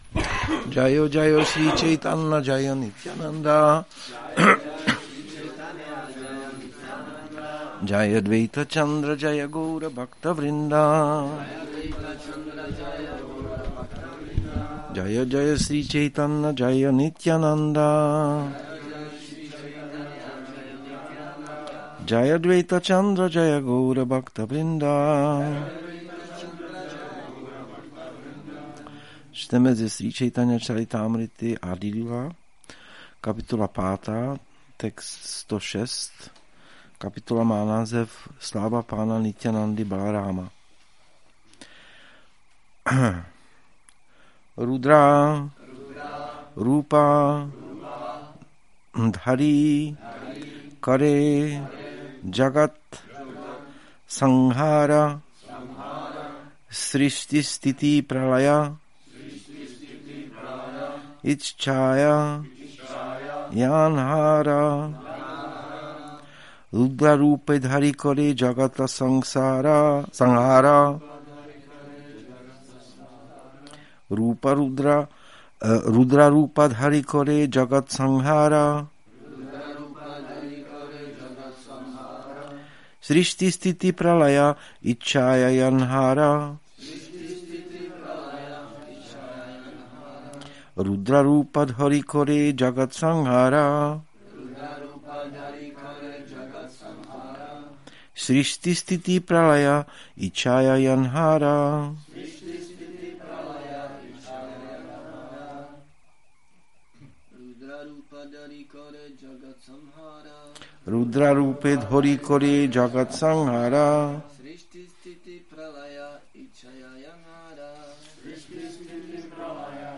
Přednáška CC-ADI-5.106